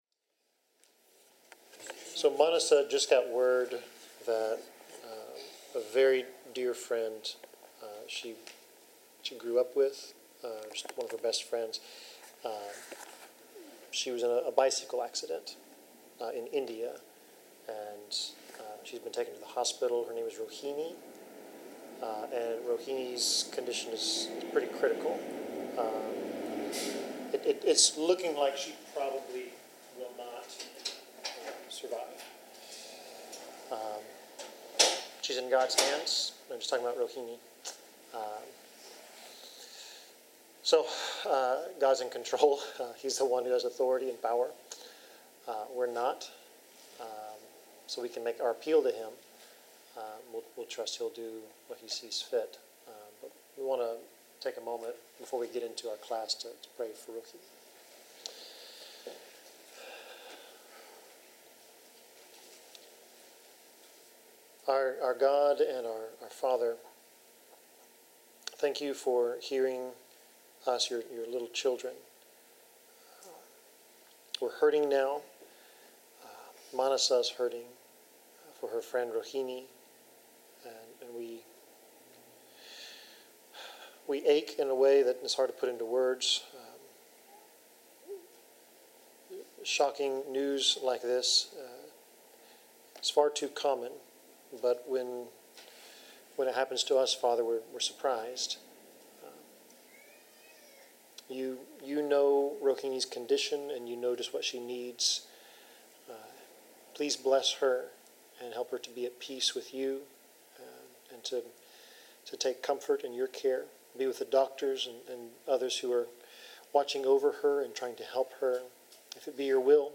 Bible class: Deuteronomy 21-22
Passage: Deuteronomy 21:10-22:12 Service Type: Bible Class